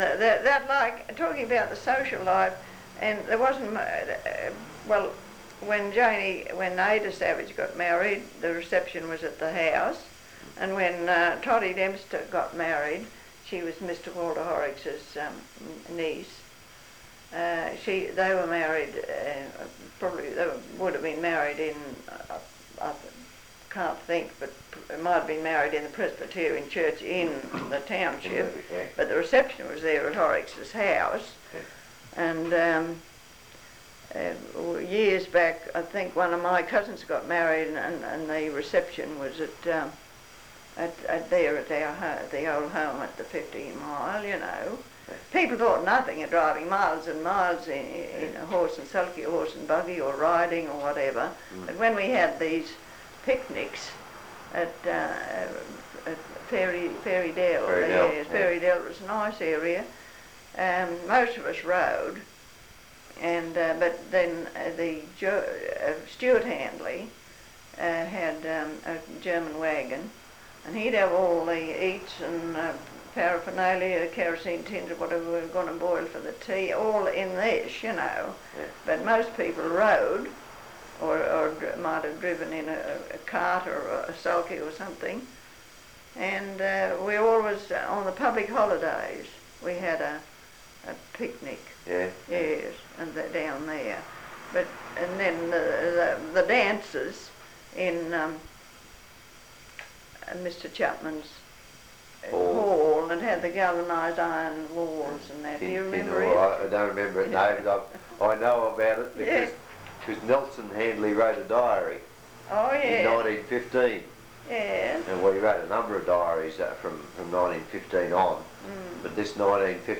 The downside was that my little sound snippet on the image of an old barn